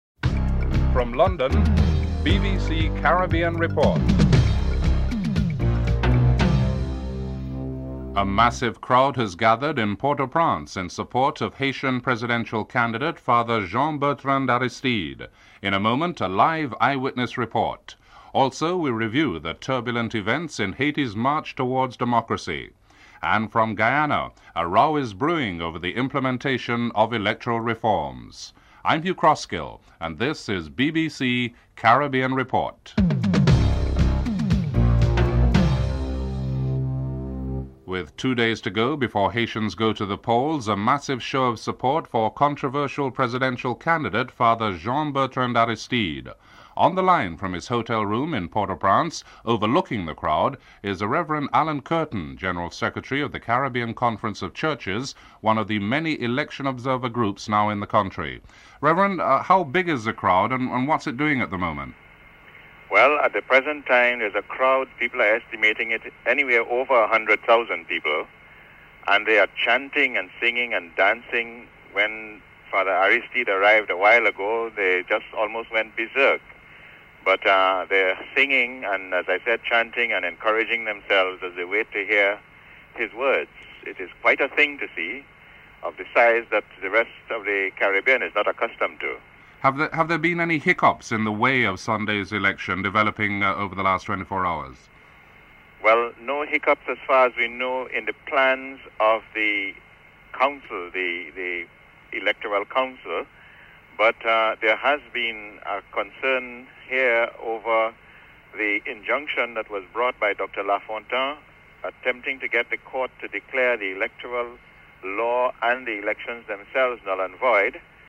1. Headlines (00:00-00:37)
via a live telephone interview